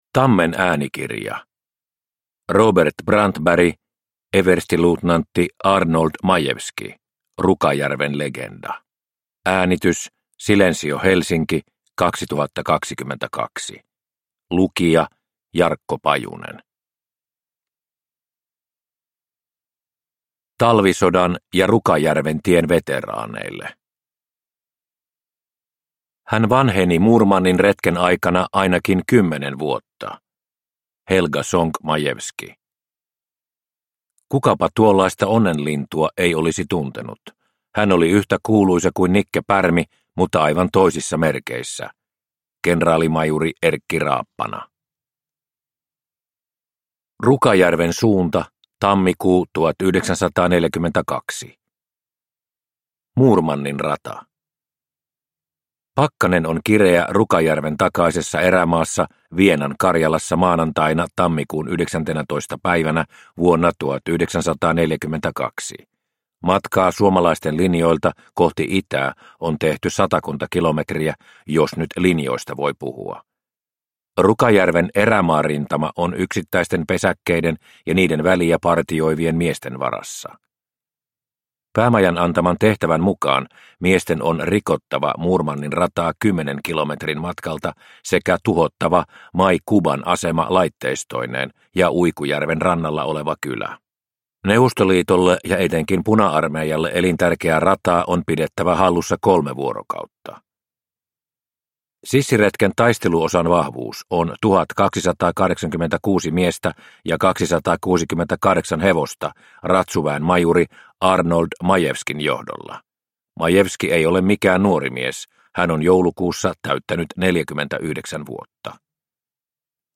Arnold Majewski – Rukajärven legenda – Ljudbok – Laddas ner